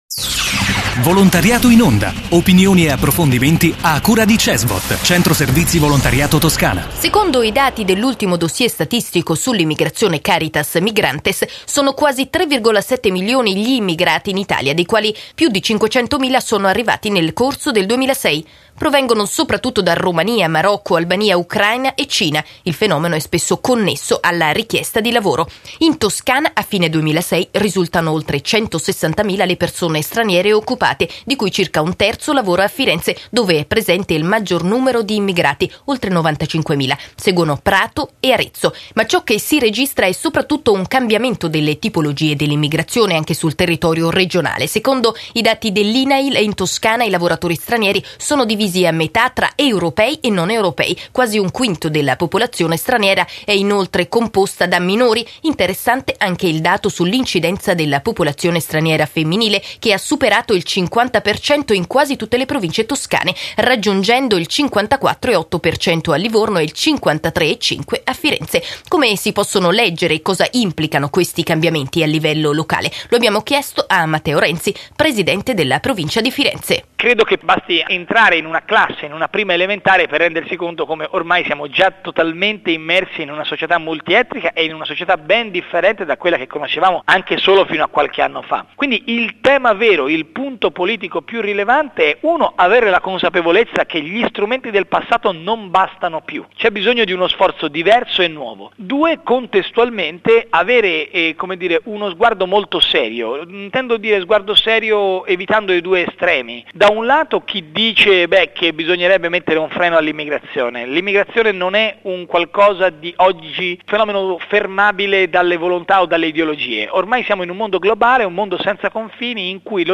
Intervista a Matteo Renzi, presidente della Provincia di Firenze